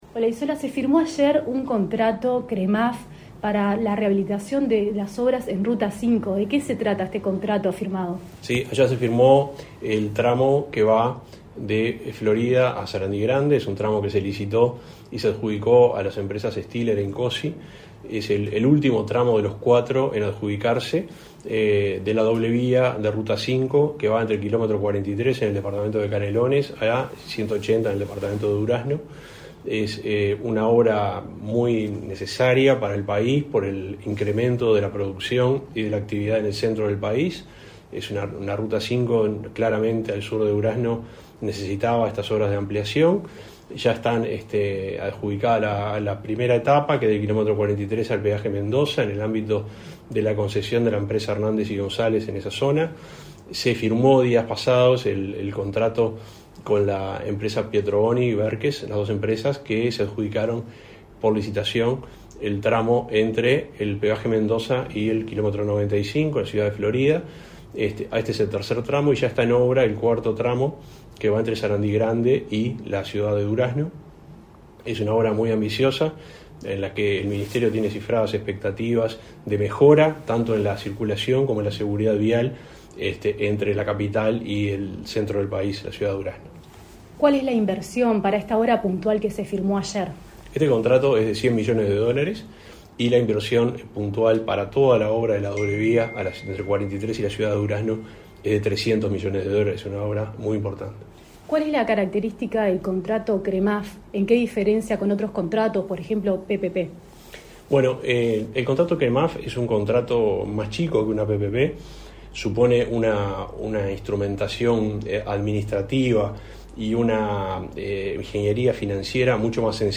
Entrevista al subsecretario del MTOP, Juan José Olaizola